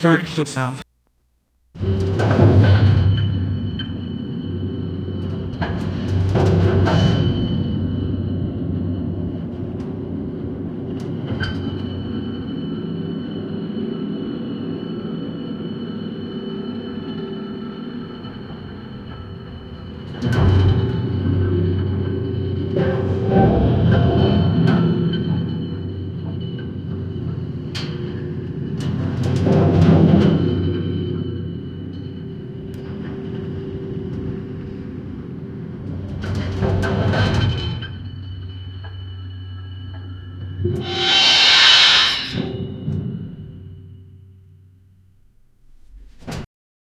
earthquake in descending elevator. Layers: deep rumbling, metallic creaks, grinding gears/cables. Build intensity: bending metal, debris crashes. Loud structural CRASH + abrupt halt. Cinematic chaos. Add bass drops/ceiling cracks. raw stress sounds. 0:47 Created Jan 28, 2025 10:25 PM
earthquake-in-descending--gonwgpem.wav